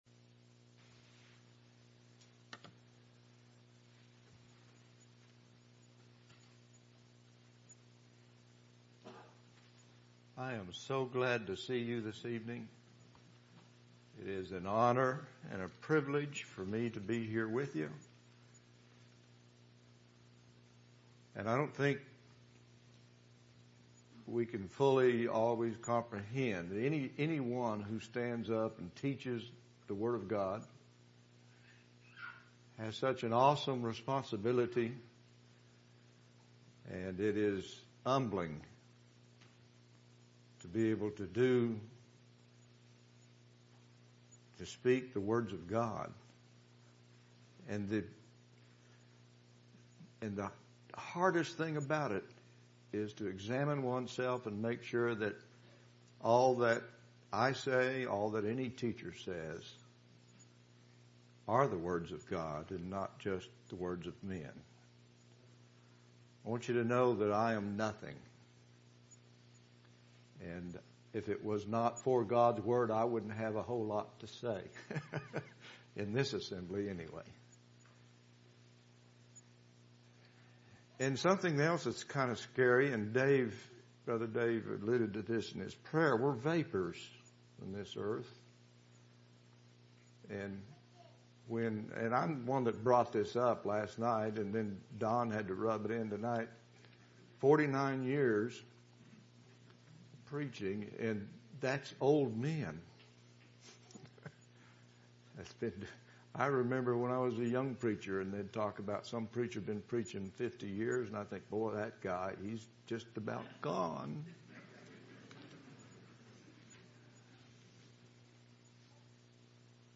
Weekly Sermons ← Newer Sermon Older Sermon →